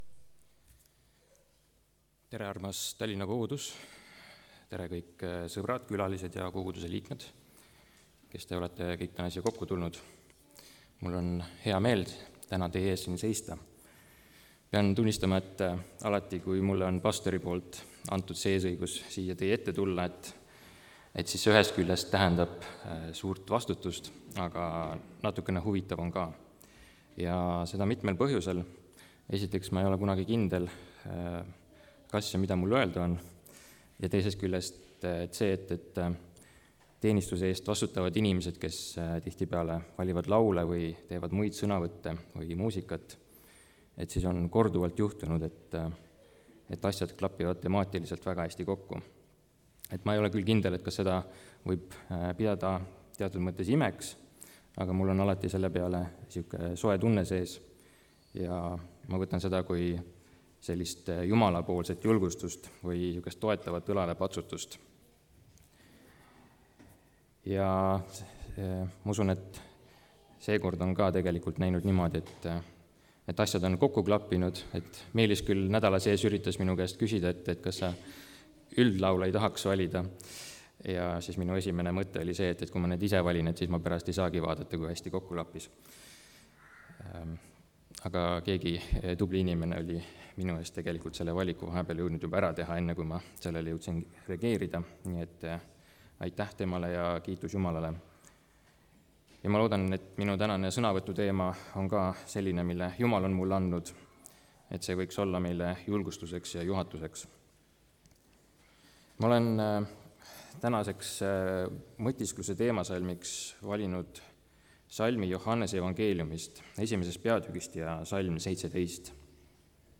kategooria Audio / Jutlused / Teised